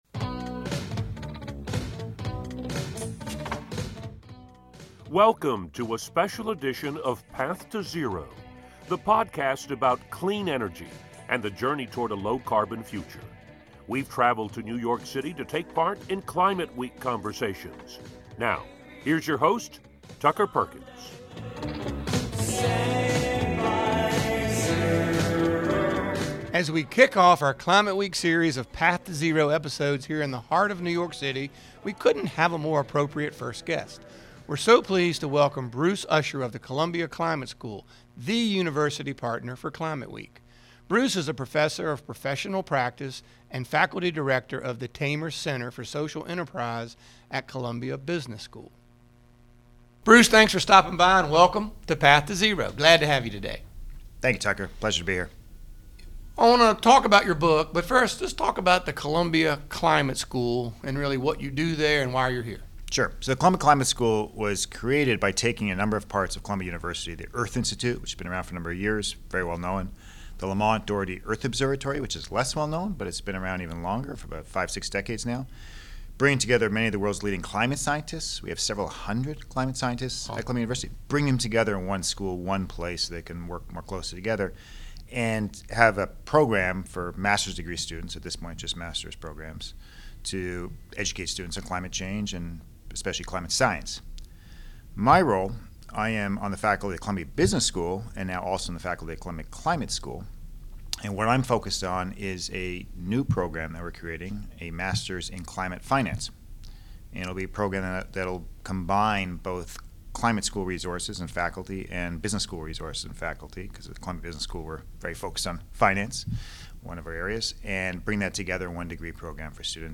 continues his series of conversations from Climate Week in New York City